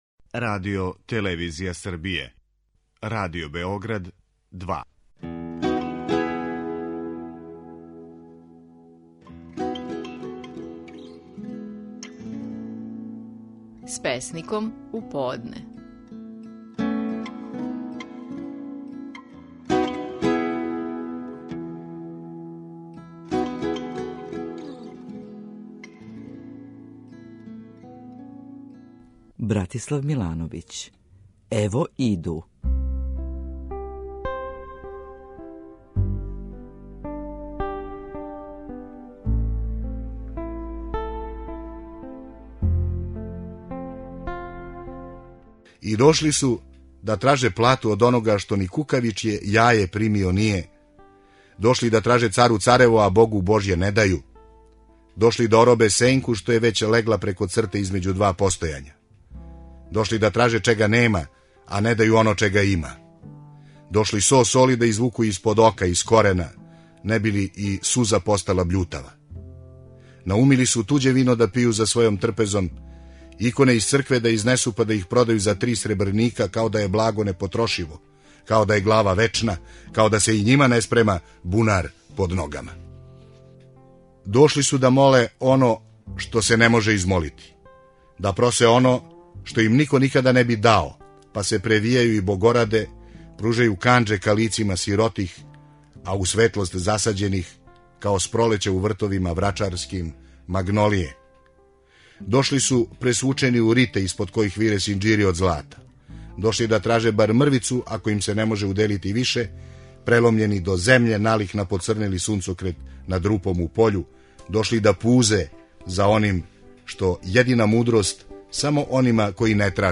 Стихови наших најпознатијих песника, у интерпретацији аутора.
Десимир Благојевић говори песму „Лов о поноћи".